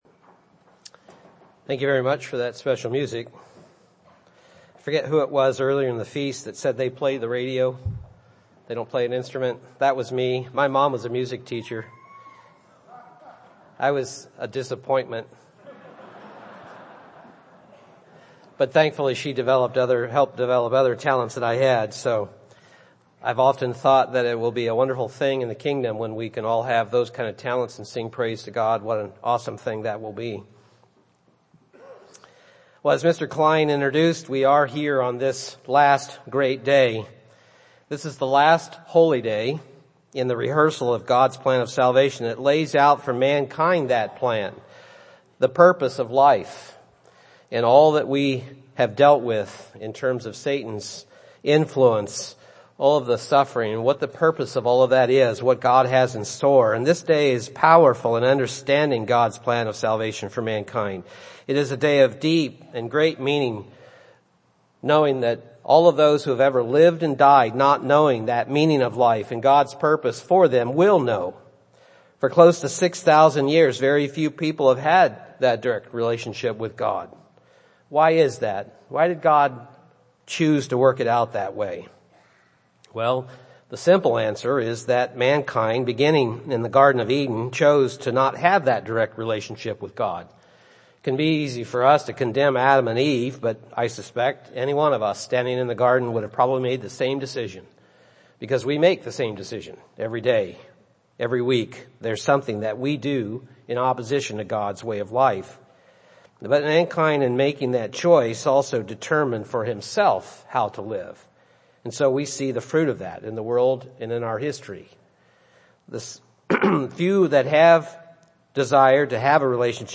UCG Sermon Notes Here we are on the Last Great Day.